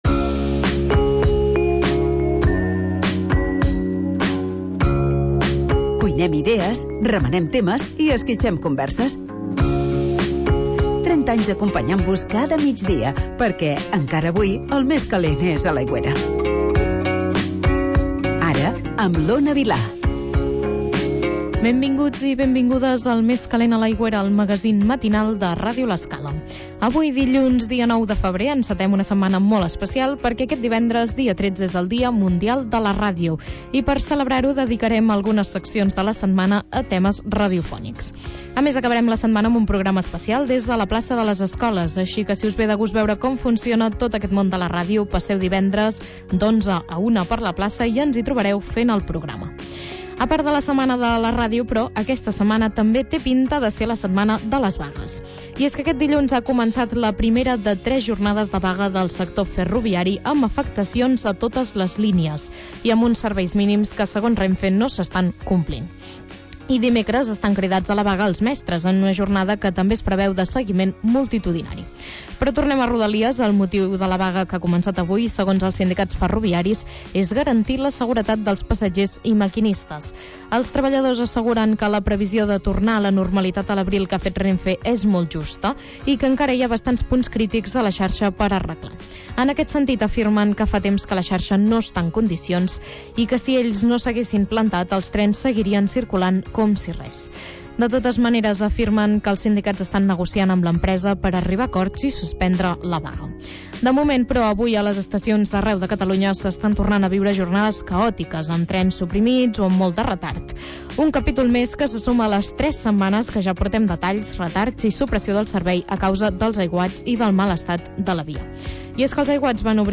Magazín d'entreteniment per passar el migdia